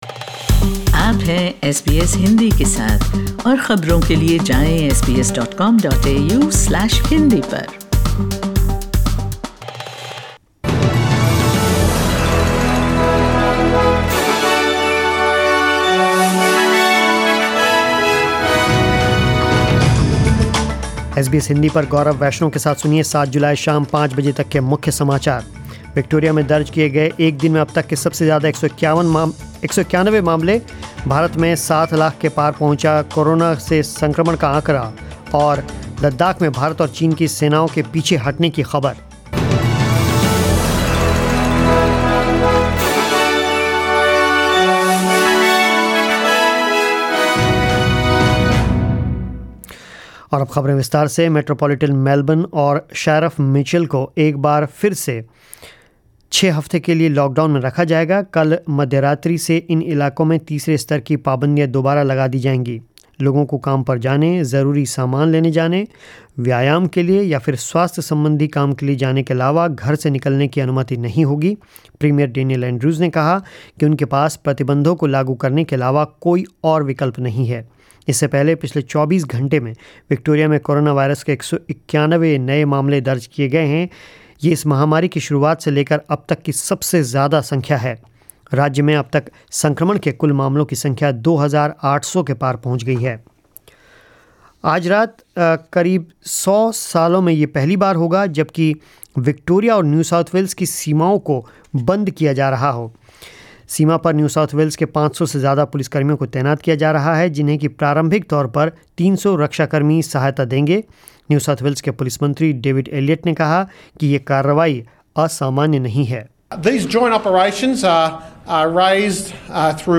News in Hindi 07 July 2020